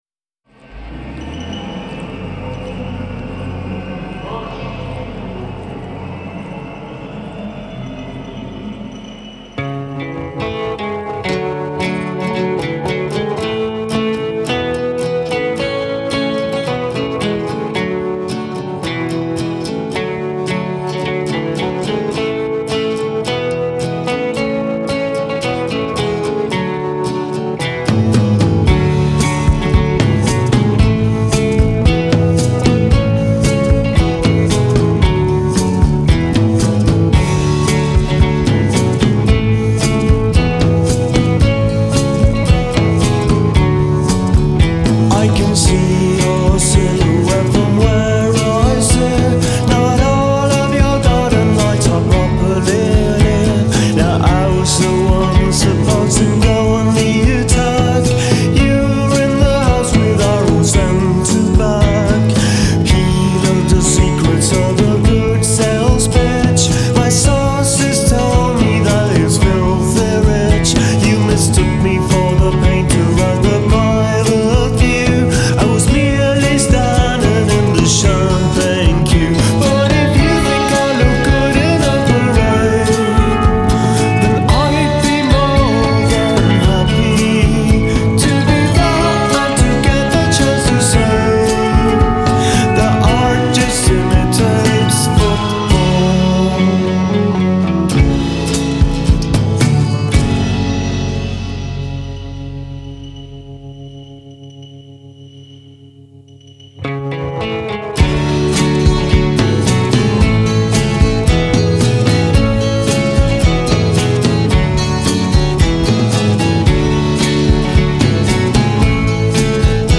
acoustic guitar, vocals
electric guitar, backing vocals
bass guitar
keyboards, backing vocals
drums
Genre: Indie Pop / Twee